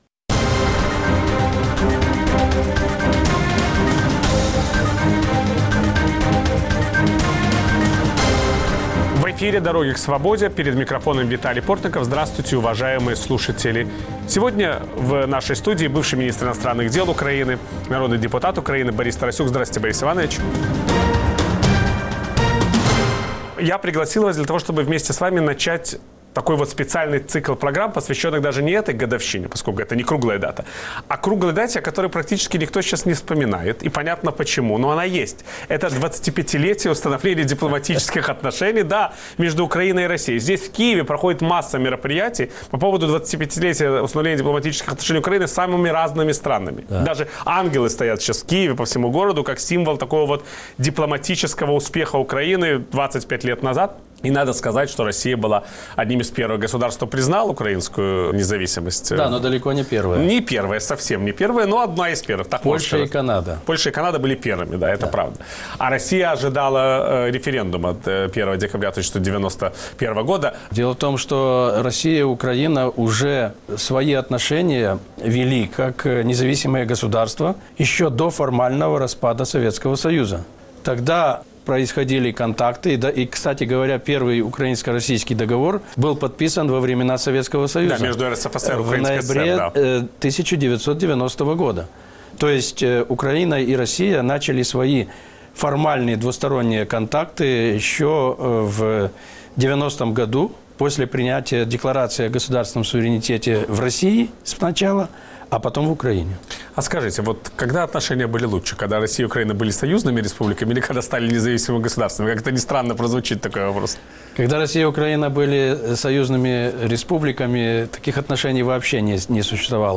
К 25-летию установления дипломатических отношений между Украиной и Россией «Дороги к свободе» предлагают слушателю специальный цикл программ. Гость сегодняшнего эфира – бывший министр иностранных дел Украины, депутат Верховной Рады Украины Борис Тарасюк.